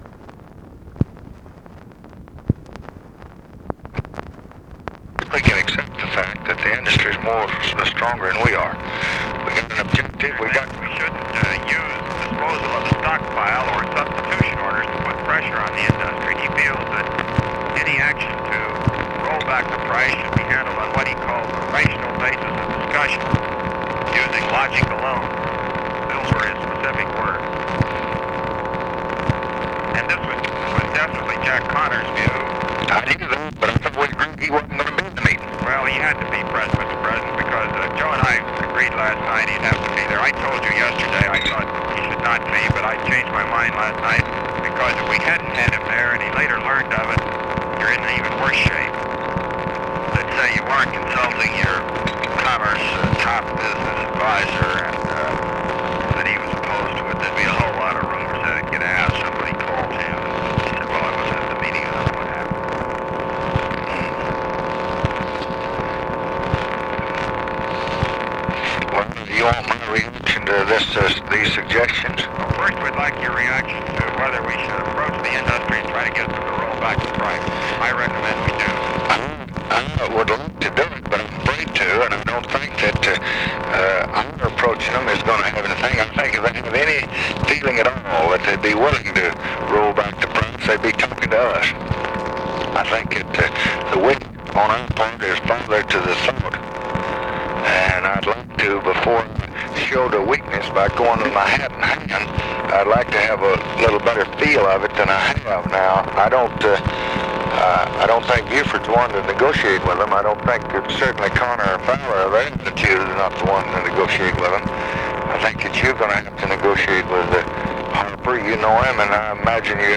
Conversation with JOSEPH CALIFANO and ROBERT MCNAMARA, November 8, 1965
Secret White House Tapes